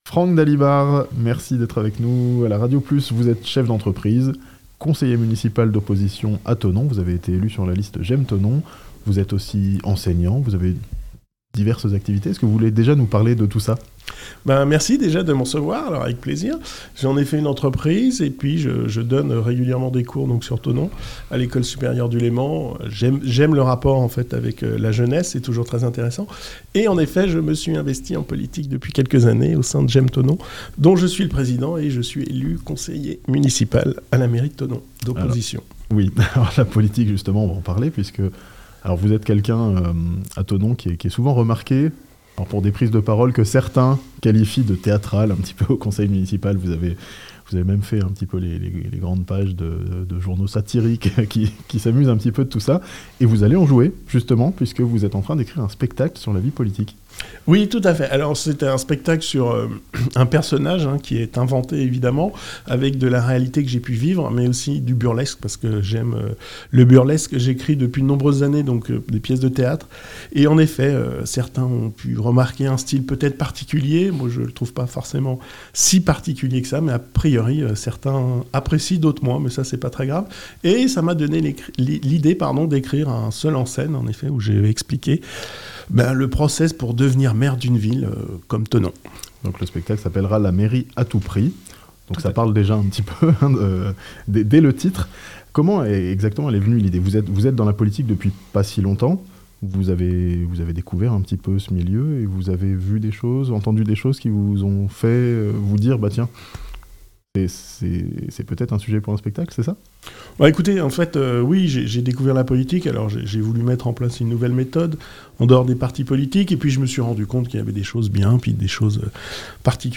Un conseiller municipal de Thonon monte sur scène, inspiré par son expérience de la vie politique (interview)